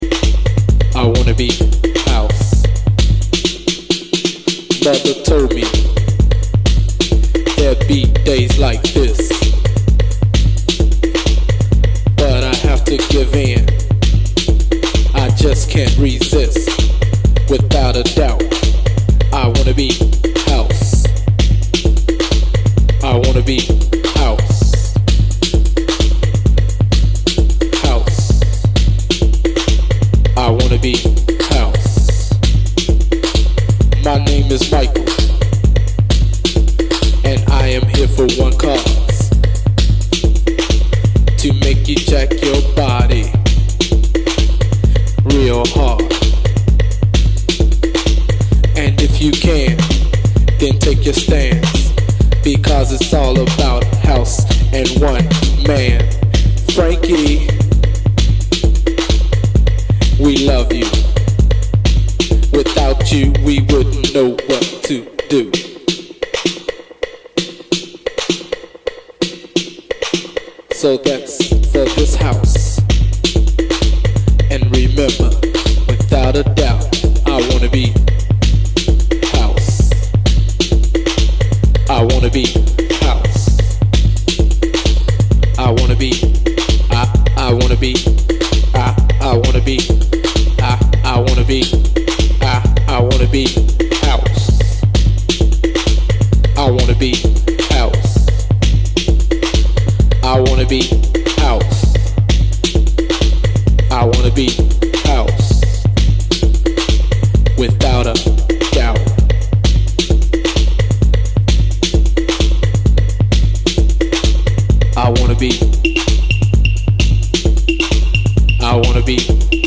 acid track